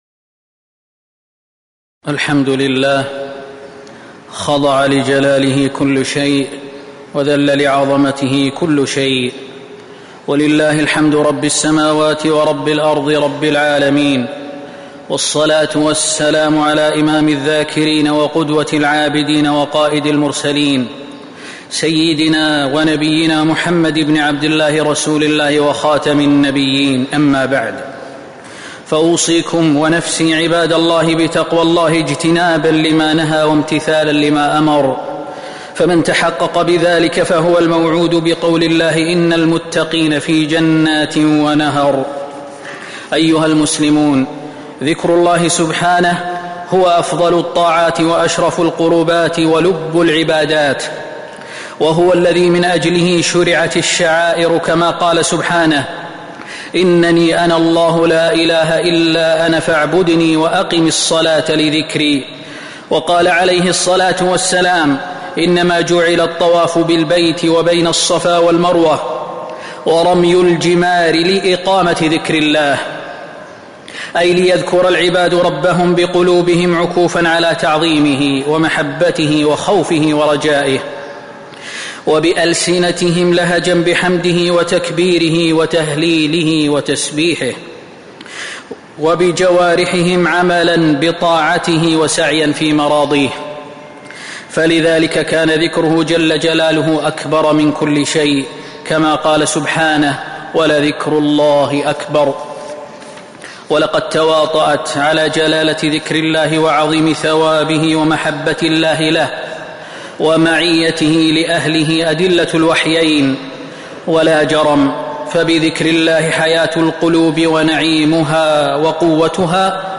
تاريخ النشر ١٥ ذو الحجة ١٤٤٥ هـ المكان: المسجد النبوي الشيخ: فضيلة الشيخ د. خالد بن سليمان المهنا فضيلة الشيخ د. خالد بن سليمان المهنا فضل الذكر